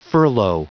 Prononciation du mot furlough en anglais (fichier audio)
Prononciation du mot : furlough